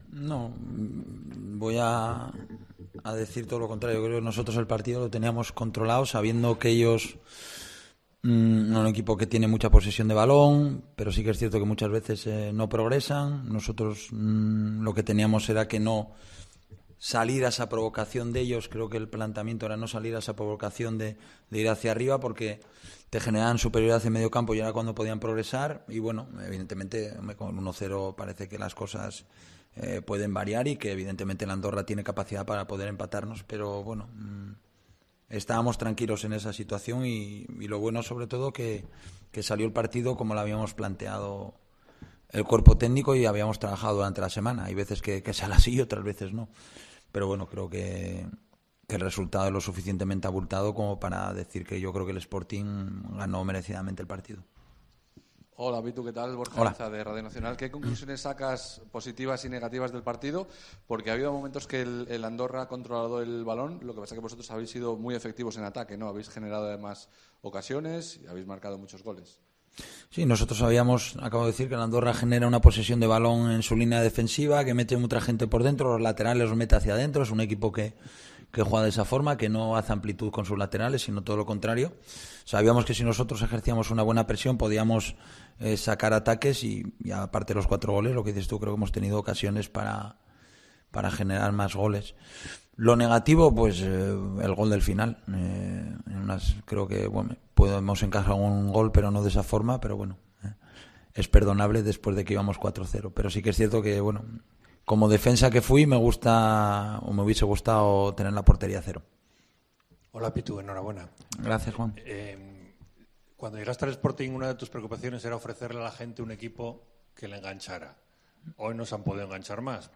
Rueda de prensa Abelardo (post Andorra)